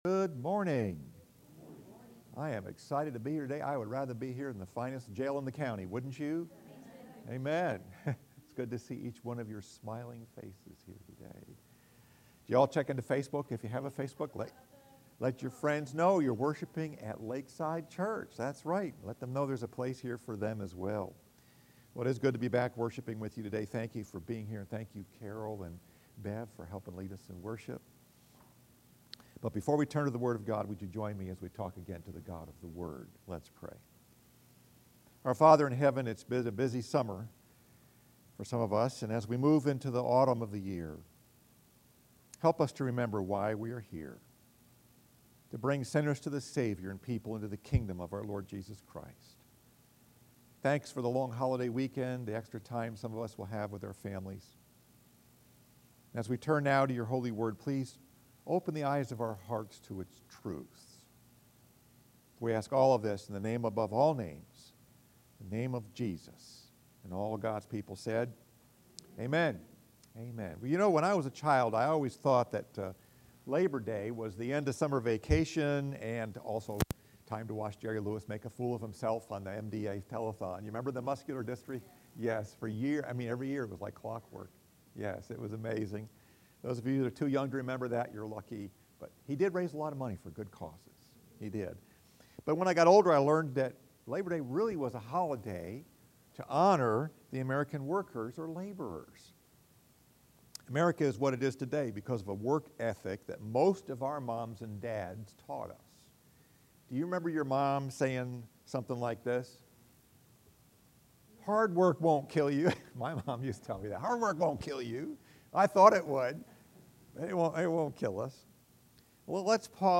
Message: “Honoring the Laborers” Scripture: 1 Thessalonians 1:1-3